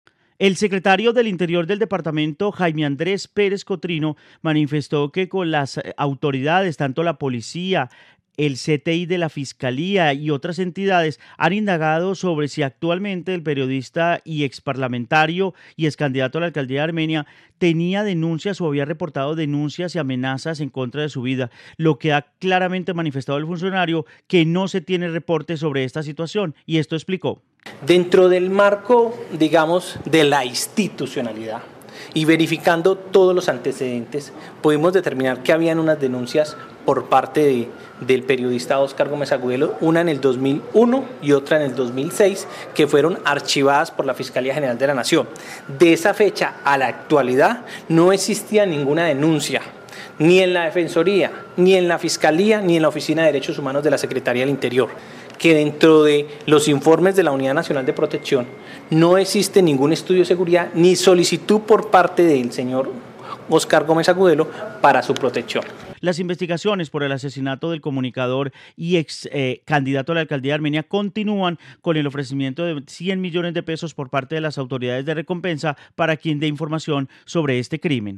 Informe investigación crimen periodista